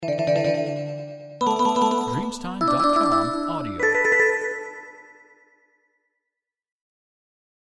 Breve carillon asiatico 0001 delle campane